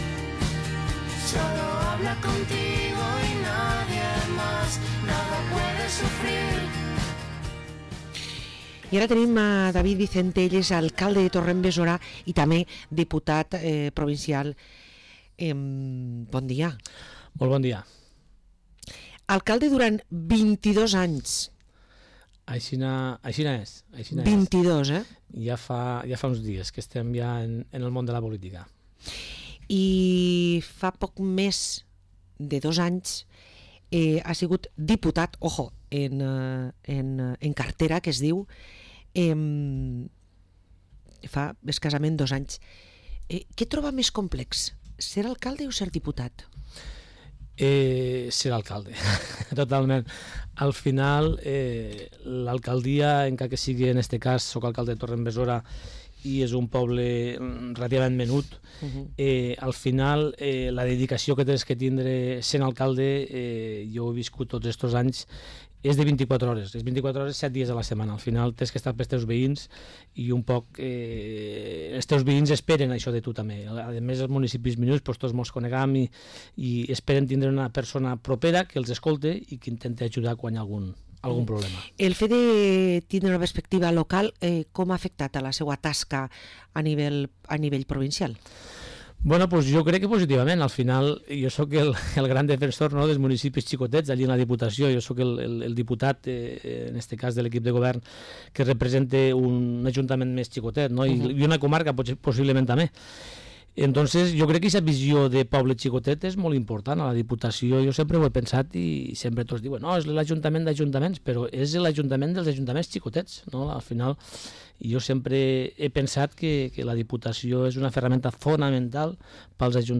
Parlem amb David Vicente, Alcalde de Torre d´En Besora i Diputat Provincial